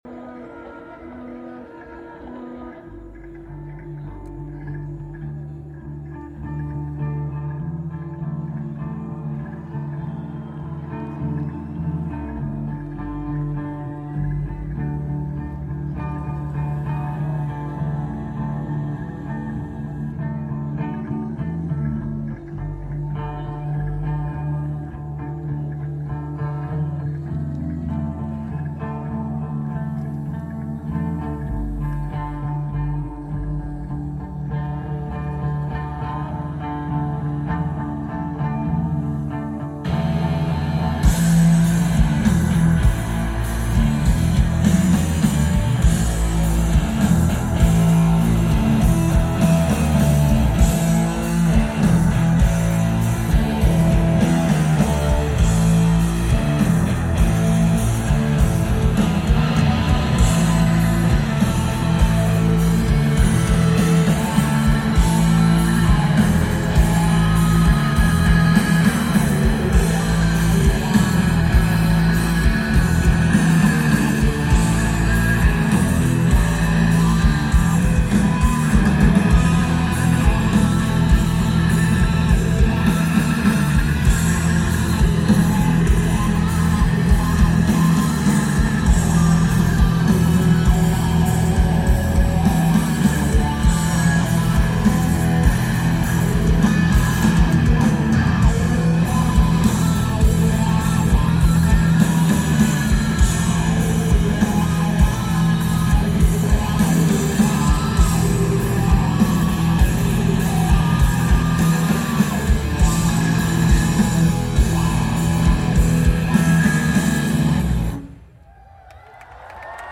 Soundwave Festival
Lineage: Audio - AUD (CA-11 + CA ST-9100 + iRiver iHP-120)
Notes: Great recording!